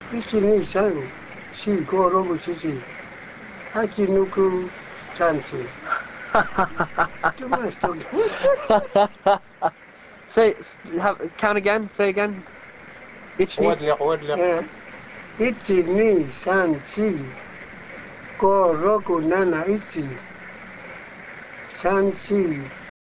Interview
Real Audio  Counting in Japanese